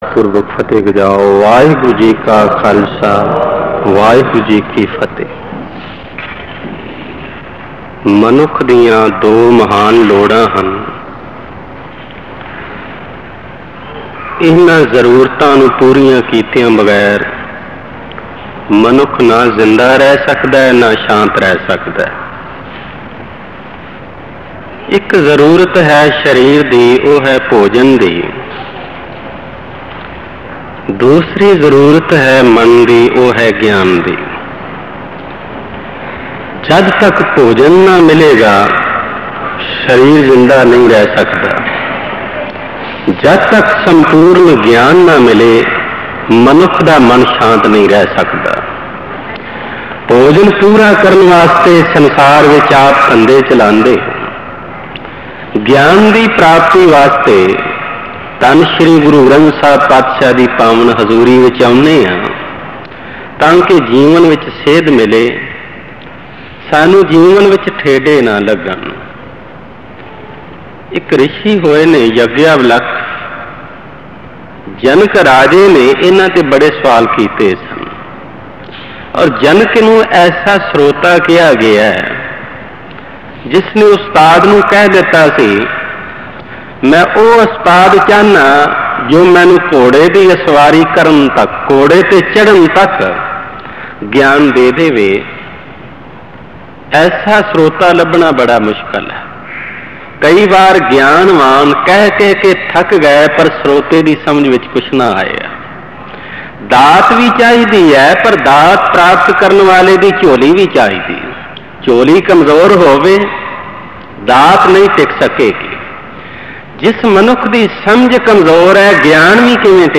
katha